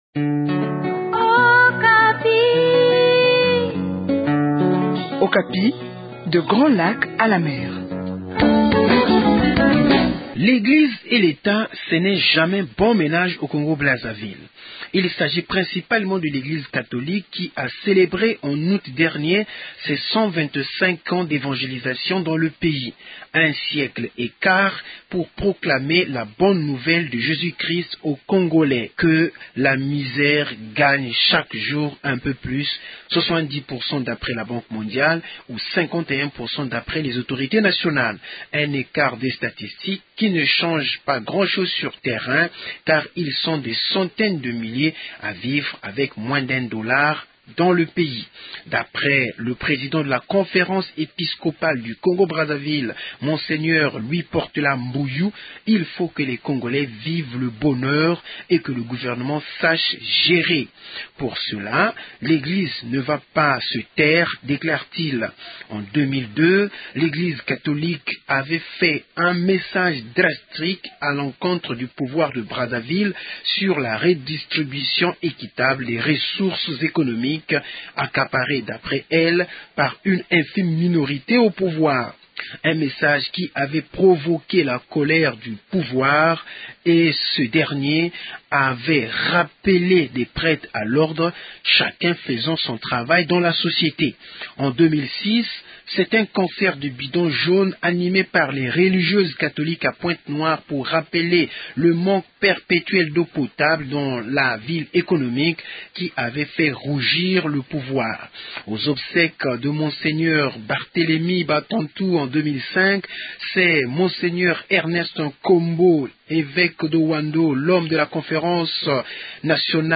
depuis Brazzaville